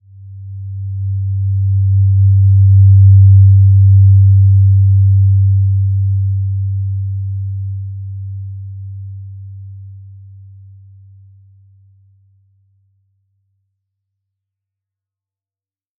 Simple-Glow-G2-mf.wav